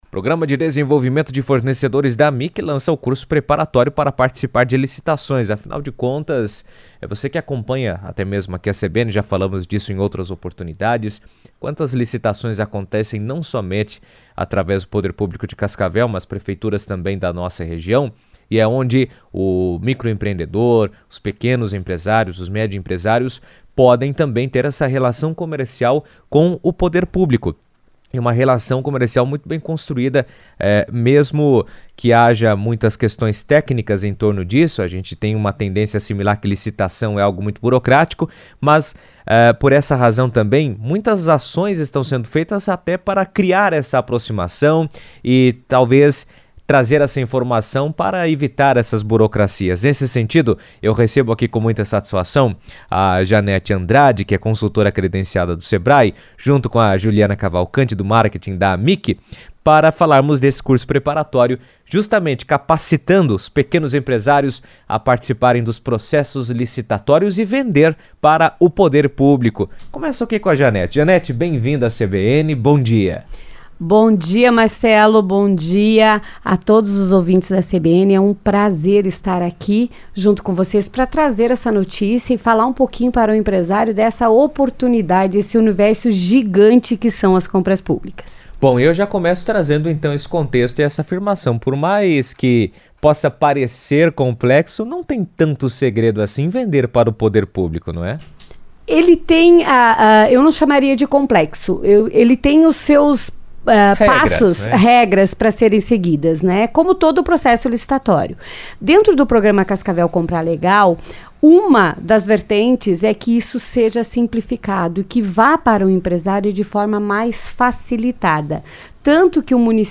falaram sobre o tema em entrevista à CBN.